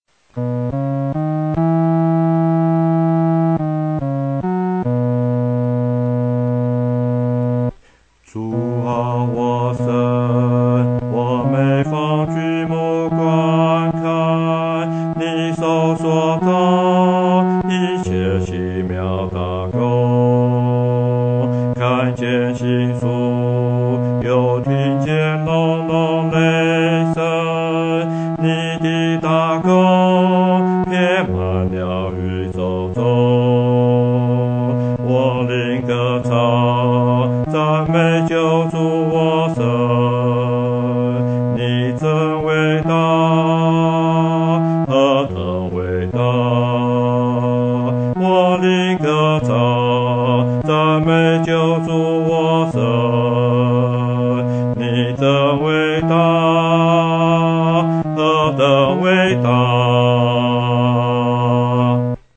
独唱（第四声）